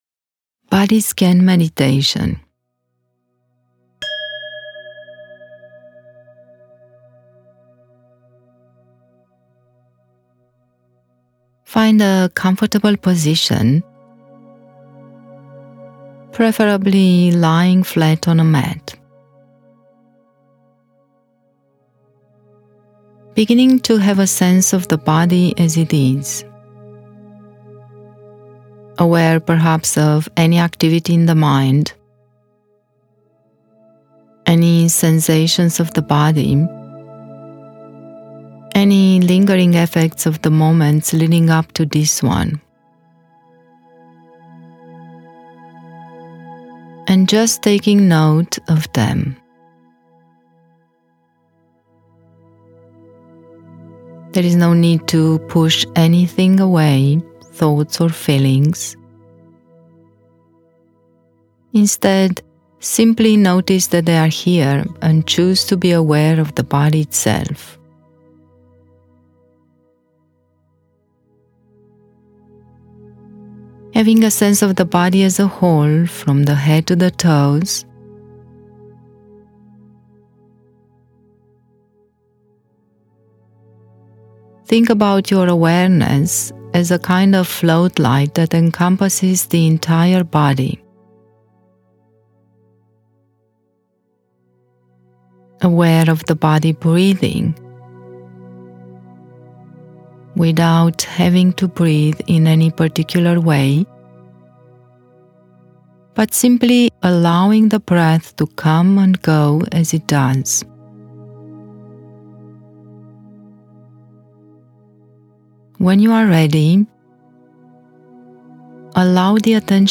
Body Scan Meditation 20min audio with music